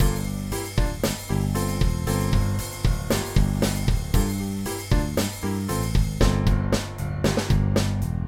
transformer-off
transformer-off.mp3